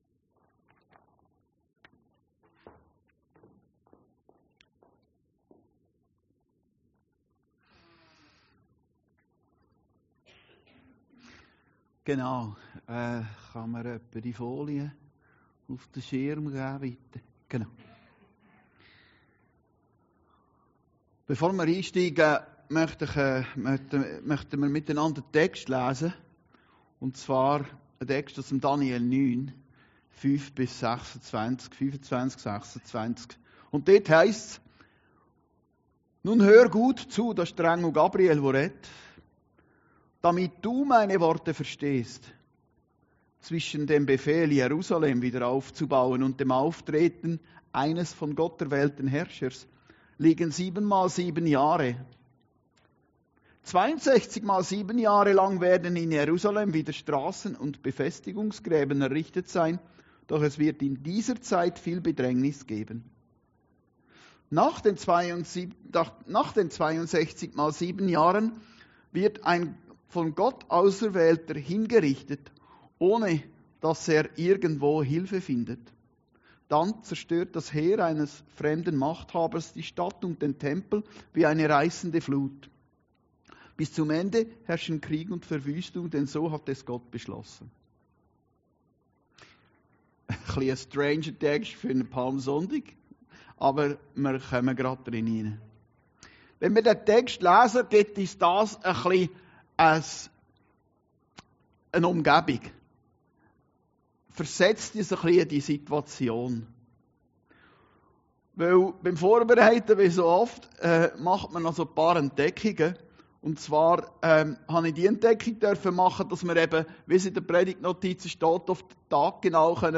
Predigten Heilsarmee Aargau Süd – Der Einzug in Jerusalem